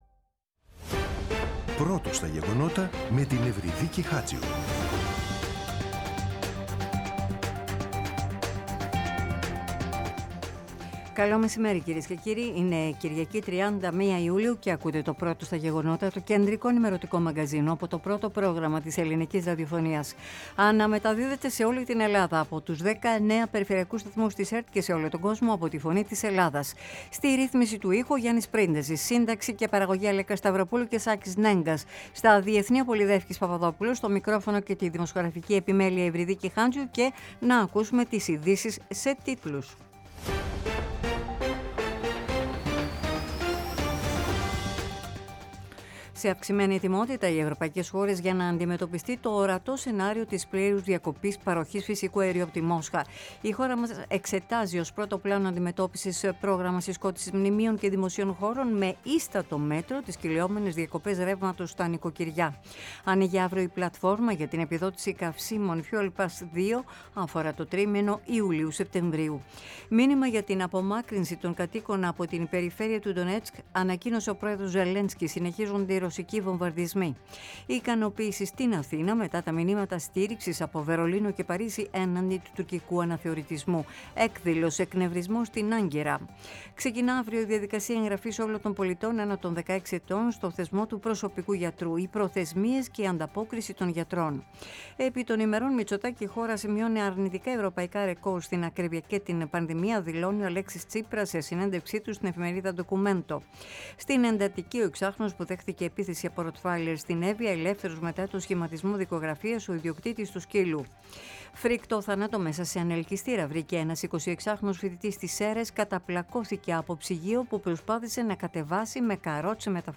“Πρώτο στα γεγονότα”. Το κεντρικό ενημερωτικό μαγκαζίνο του Α΄ Προγράμματος, κάθε Σάββατο και Κυριακή, στις 14.00. Με το μεγαλύτερο δίκτυο ανταποκριτών σε όλη τη χώρα, αναλυτικά ρεπορτάζ και συνεντεύξεις επικαιρότητας.